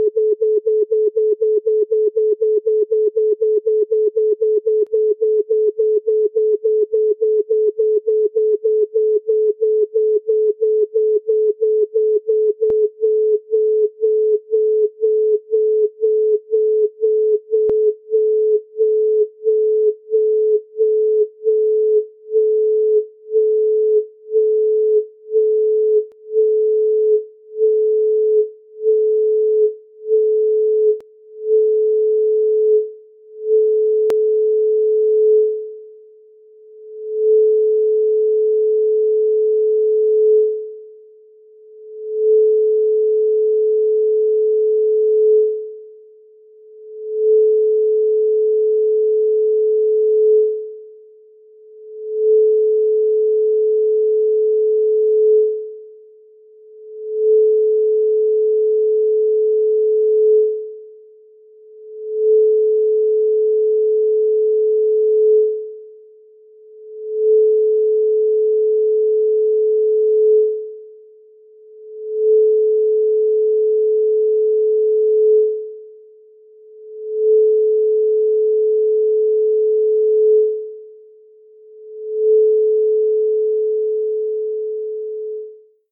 Les frequences associées aux différents pigments font référence à la tradition en lien avec les chakras d'energie du corps
432Hz-Vermillon.mp3